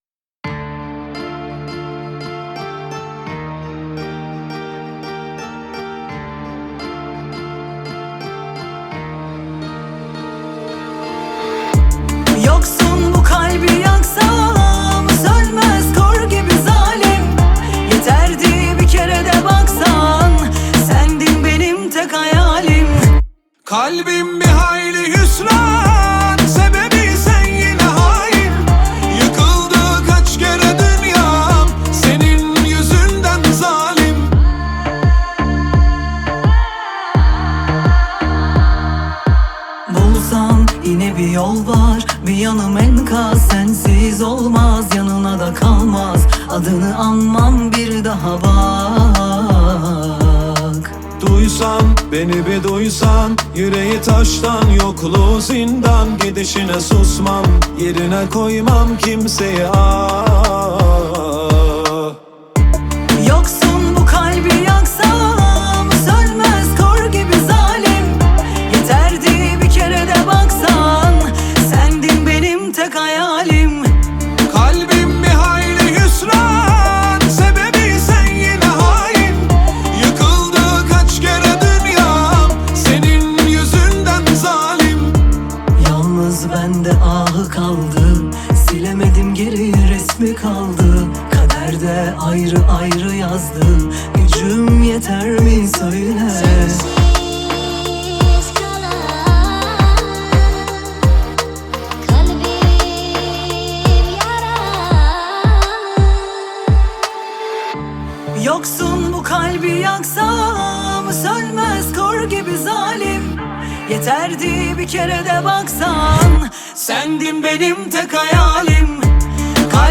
Bağlama
آهنگ ترکیه ای